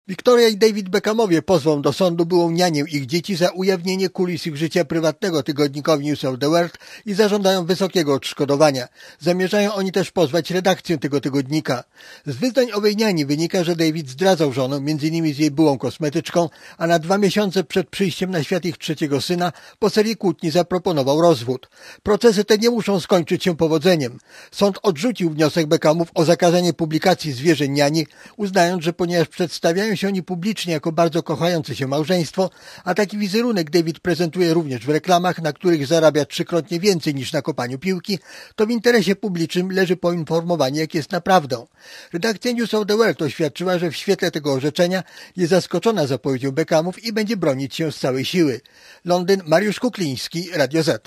Relacja
beckham-proces.mp3